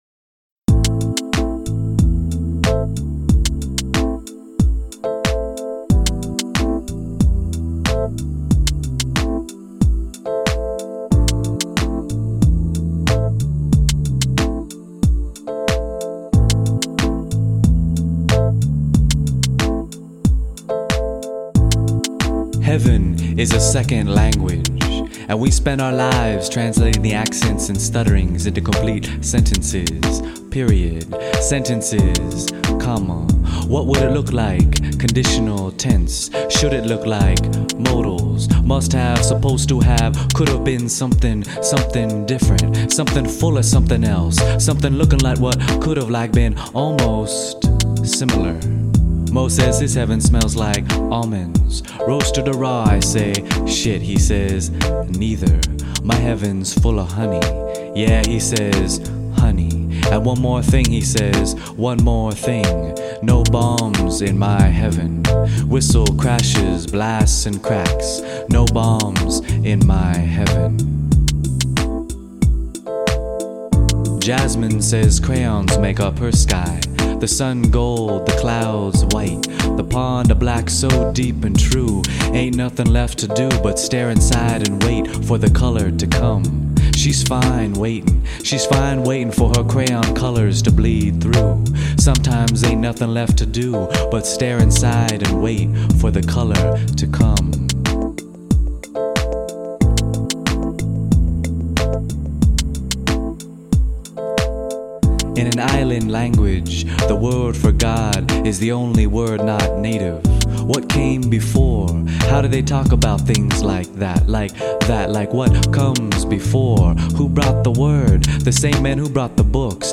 spoken word & music album